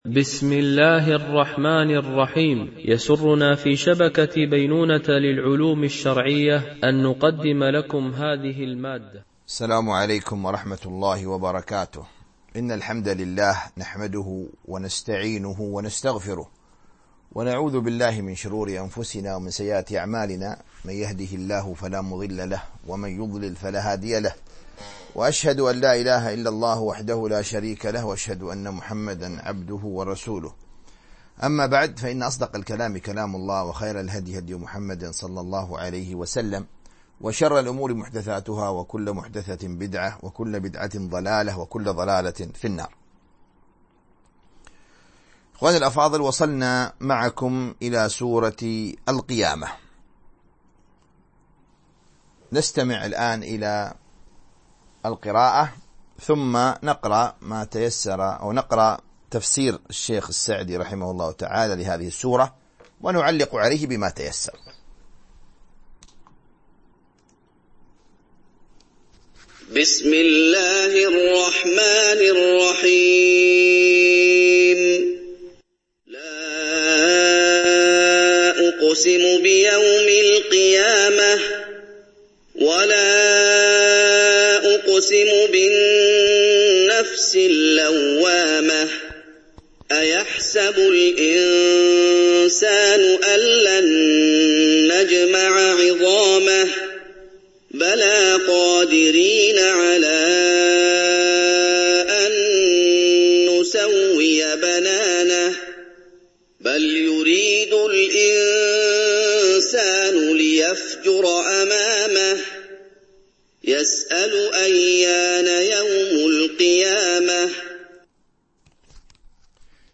تفسير جزء تبارك - الدرس 15 ( تفسير سورة القيامة )